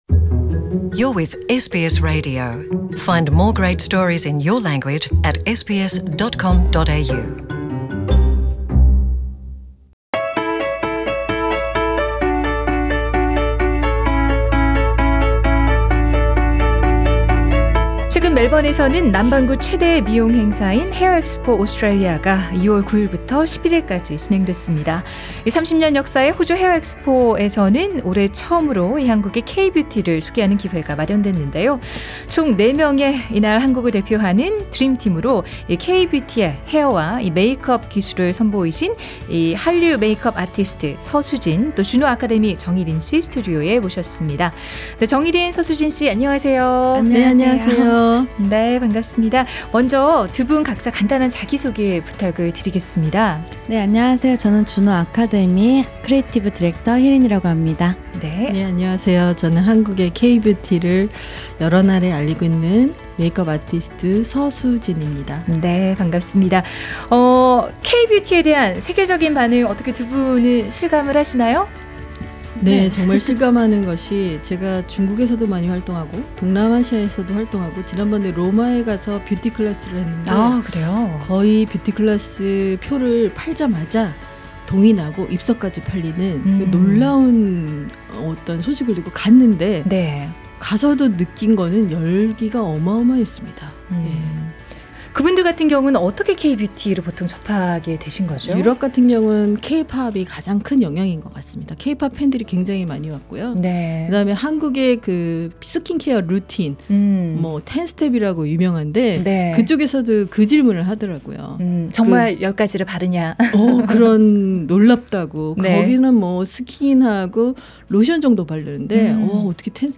The full interview with two K-Beauty experts is available on the podcast above.